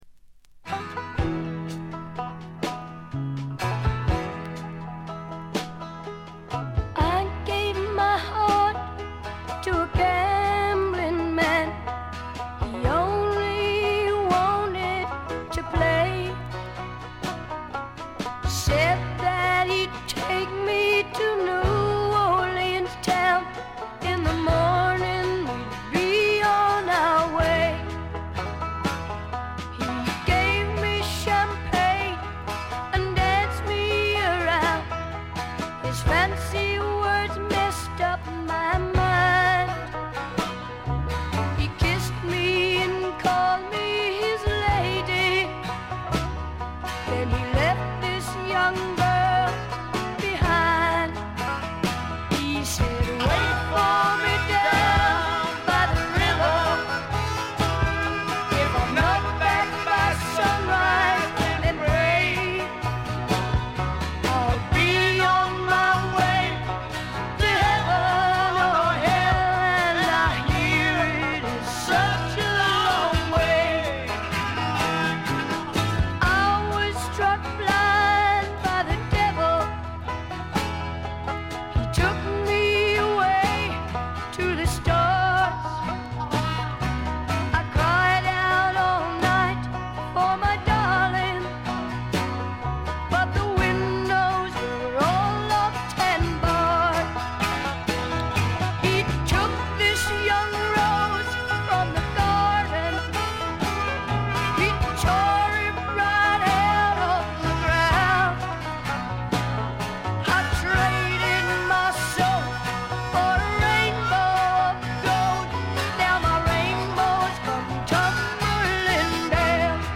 ホーム > レコード：英国 スワンプ
録音はロンドンとナッシュビルで録り分けています。
試聴曲は現品からの取り込み音源です。